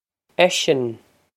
Eisean esh-on
This is an approximate phonetic pronunciation of the phrase.